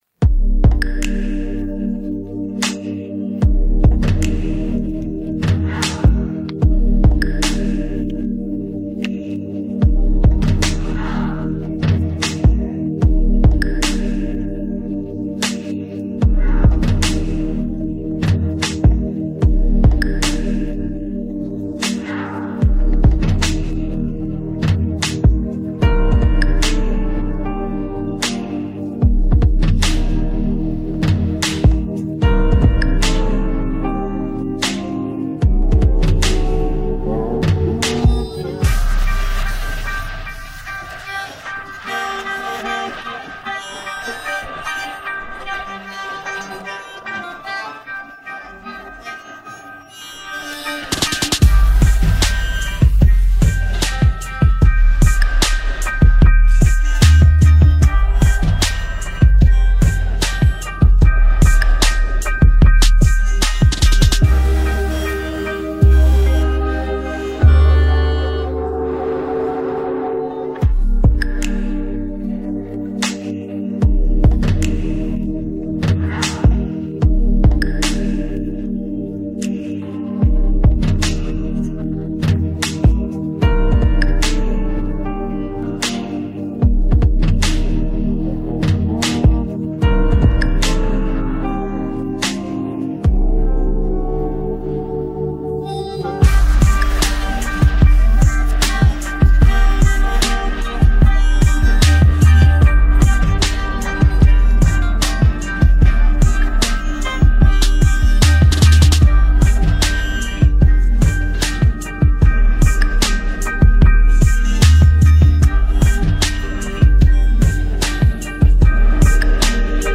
Download Posted in Instrumentals .